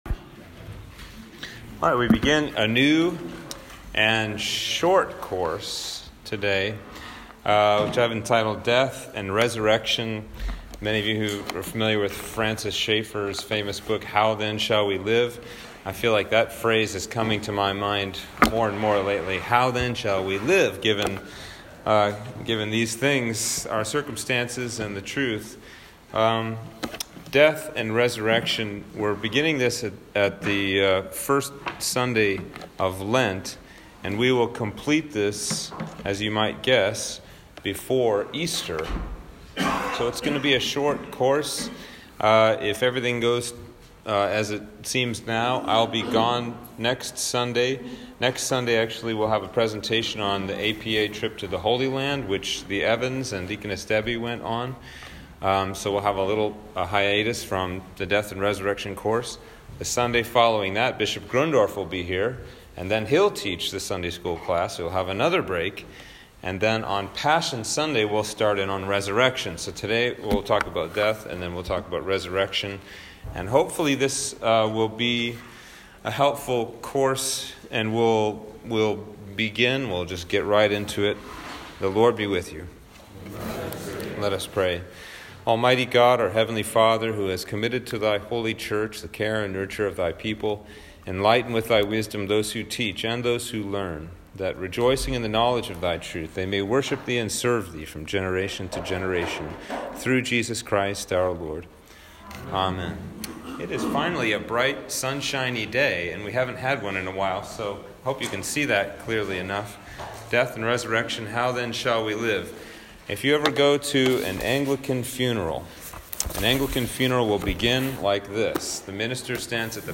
A two session course on death and resurrection